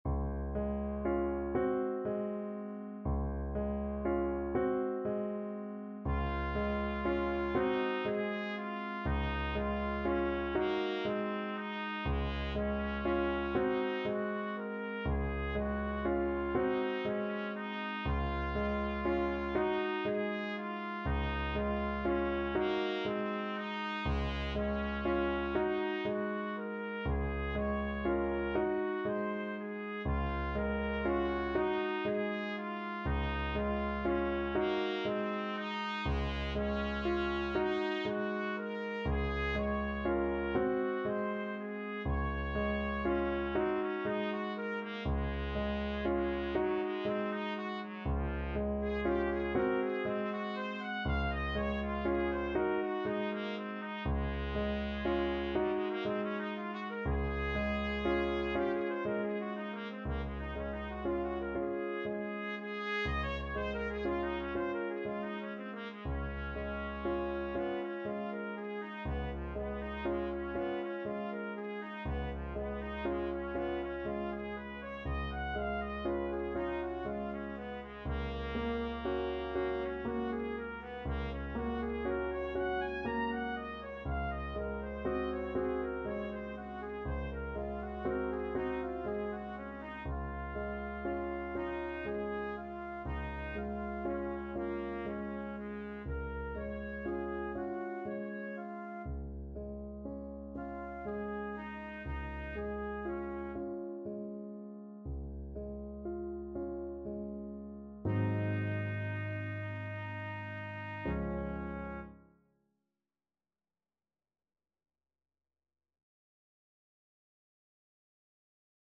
Classical Chopin, Frédéric Berceuse, Op.57 Trumpet version
Trumpet
6/8 (View more 6/8 Music)
F4-Bb6
Db major (Sounding Pitch) Eb major (Trumpet in Bb) (View more Db major Music for Trumpet )
Andante =120
berceuse-op-57_TPT.mp3